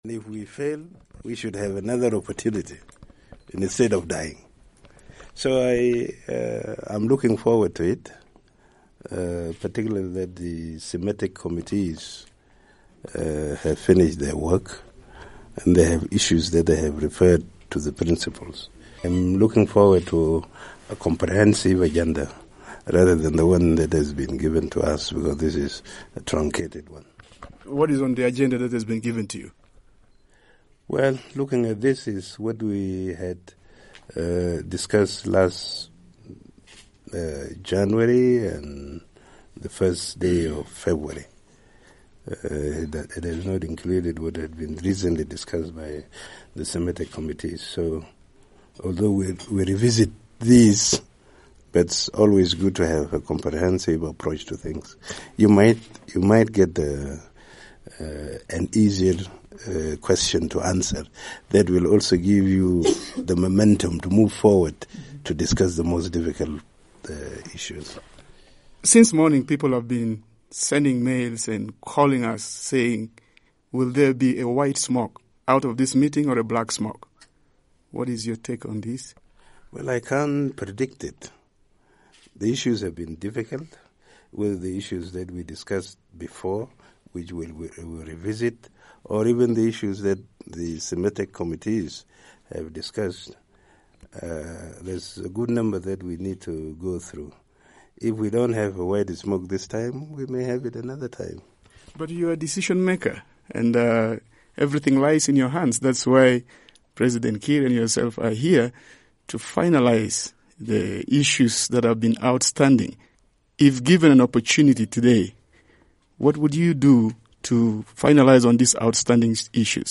at Addis Ababa talks, March 3, 2015.